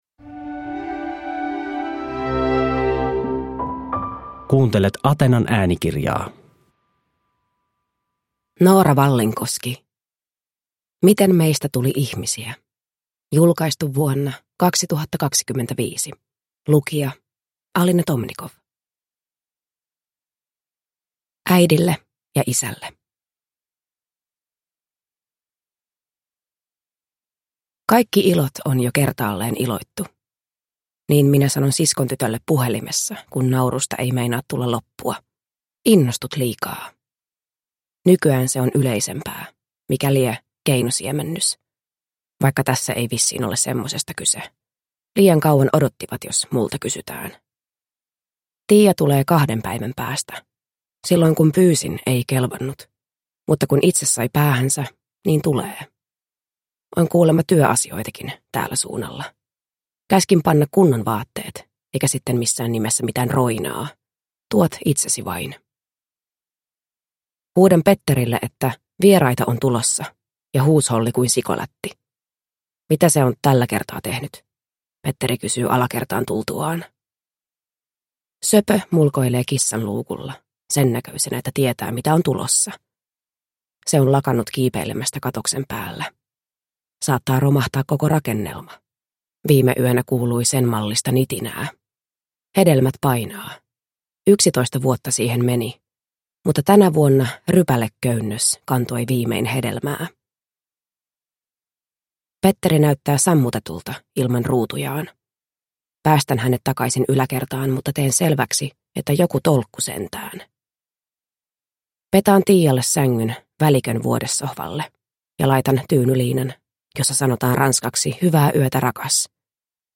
Miten meistä tuli ihmisiä (ljudbok) av Noora Vallinkoski